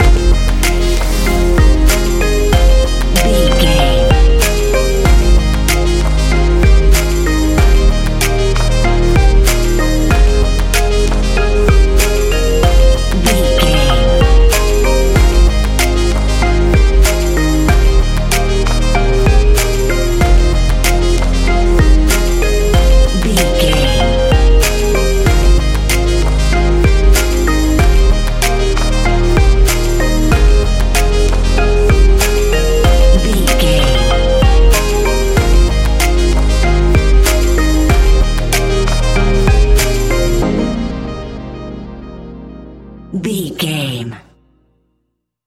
Ionian/Major
F♯
house
electro dance
synths
techno
trance